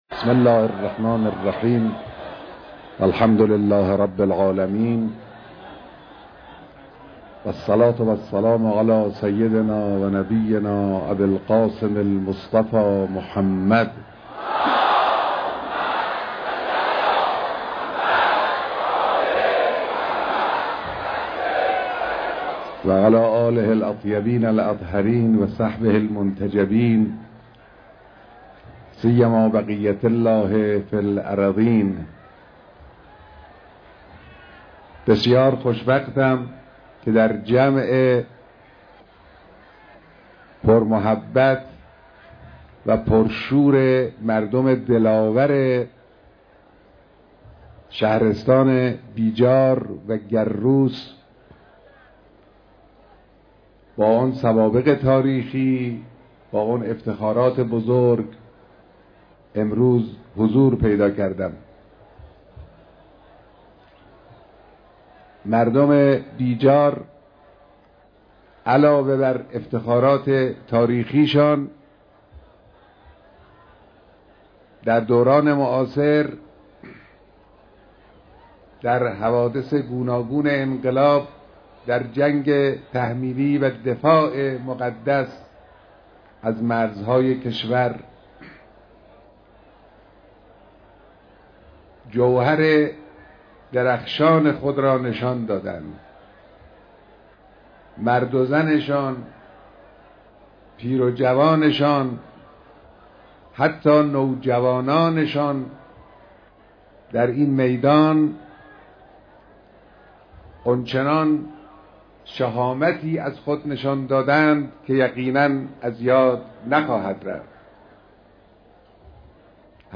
اجتماع پرشكوه مردم بيجار